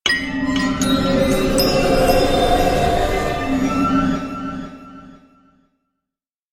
На этой странице собраны загадочные звуки порталов — от металлических резонансов до глубоких пространственных эффектов.